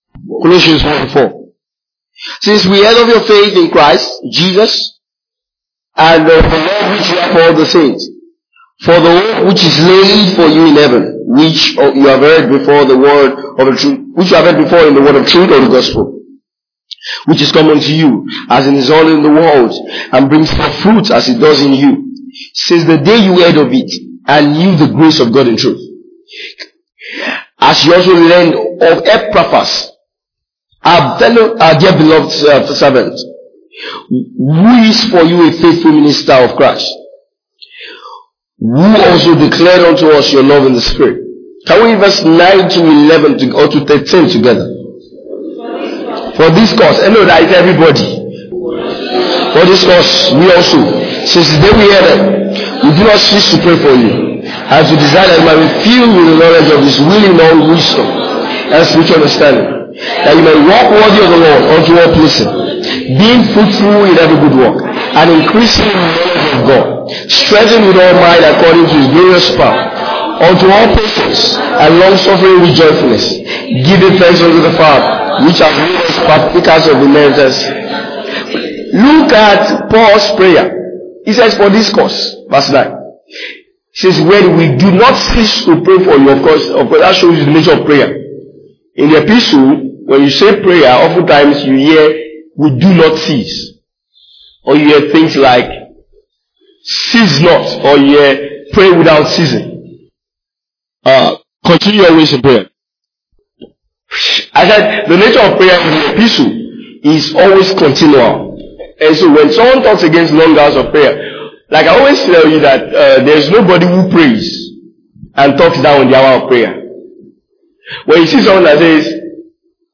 2020 Glorious House Church Teachings.